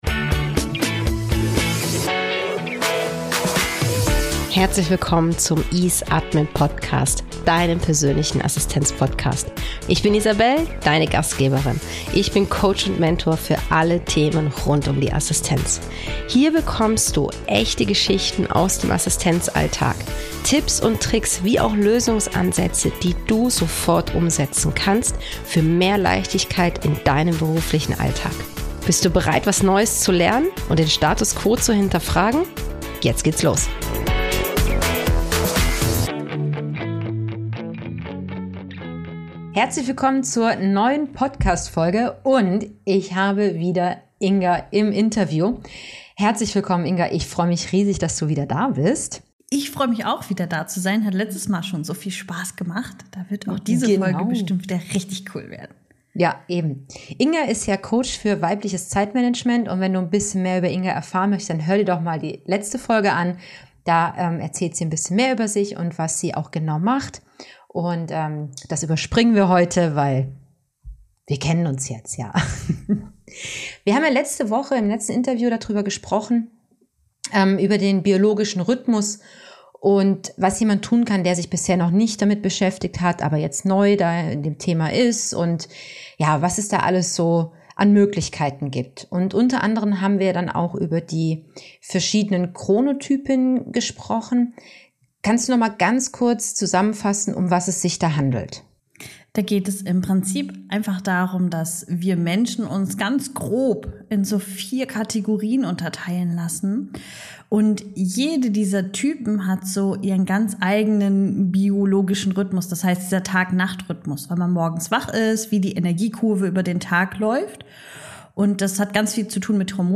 Sie spricht über die Bedeutung des biologischen Rhythmus, die verschiedenen Chronotypen und die Vorteile für das Unternehmen und die Mitarbeitenden, wenn sie mehr Freiheiten und Flexibilität haben. Das gesamte Interview kannst du dir im EaseAdmin Podcast – Folge #21 Lebst du schon oder buckelst du noch? anhören.